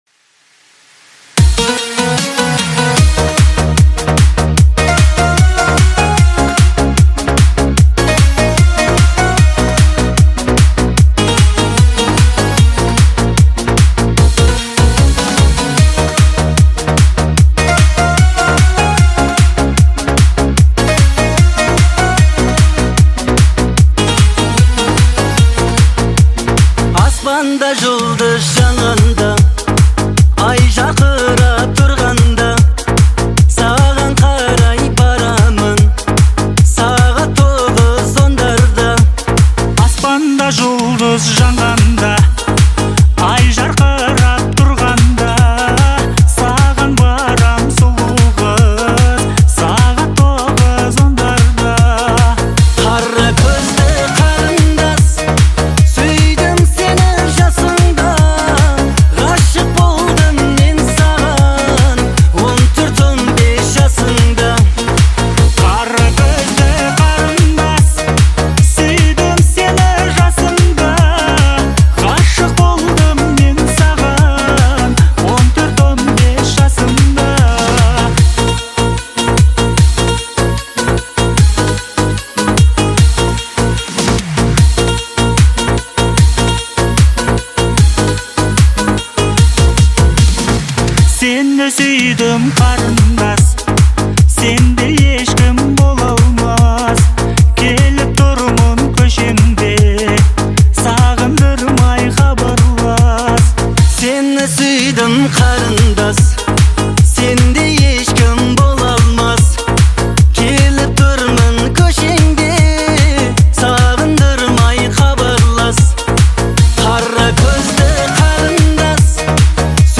это трек в жанре поп с элементами народной музыки